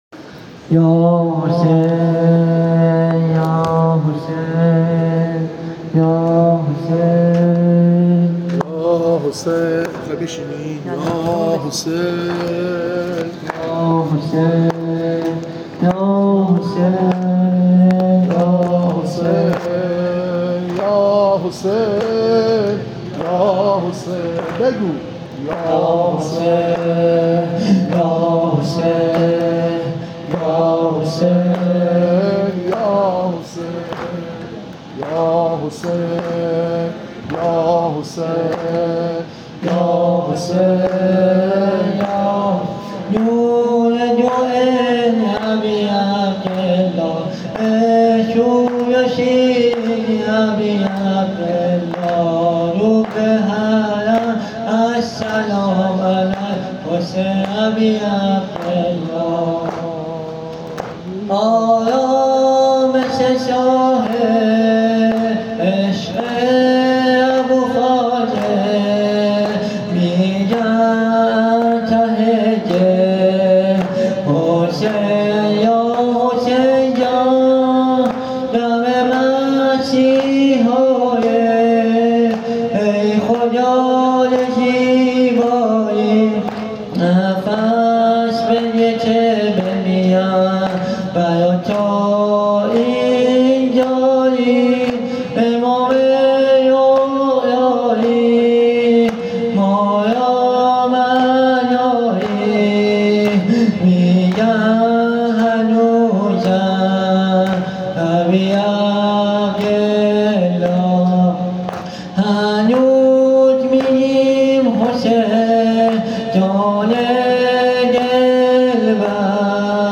زمینه اربعین حسینی
هیئت روضه الزهرا تهران